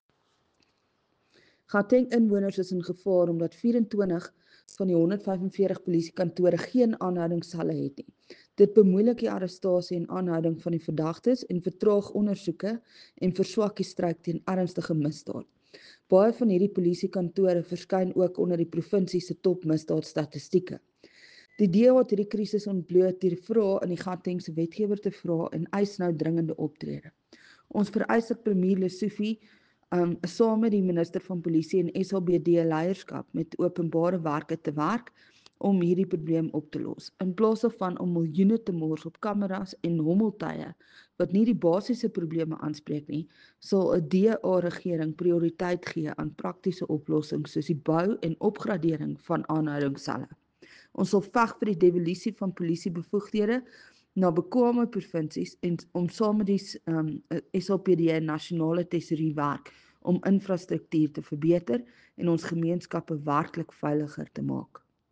Afrikaans soundbites by Crezane Bosch MPL.